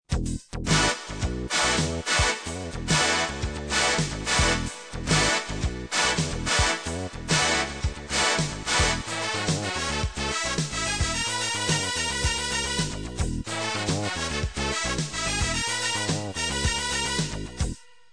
estratto della parte del basso synth